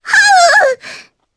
Requina-Vox_Damage_jp_01.wav